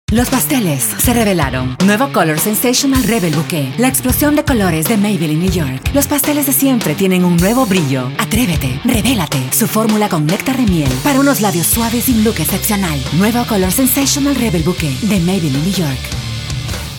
Espanhol - América Latina Neutro
Voz Madura 00:17